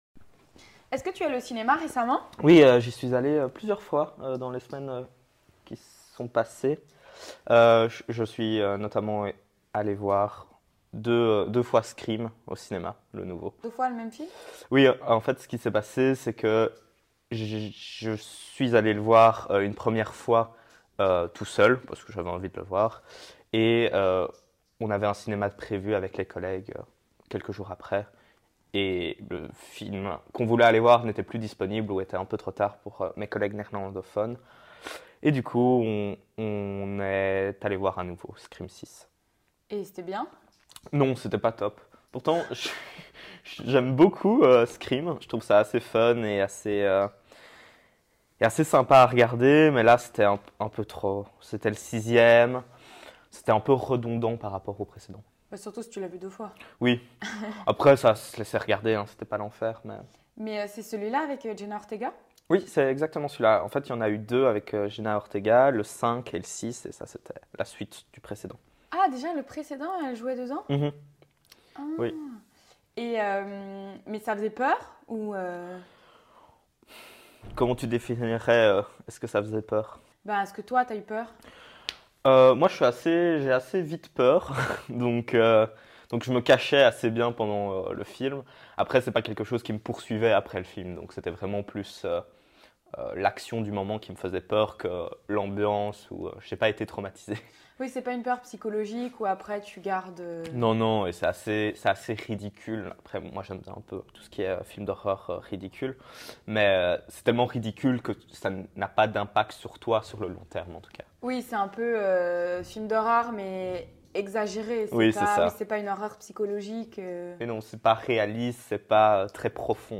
Parler de cinéma (conversation avec mon frère)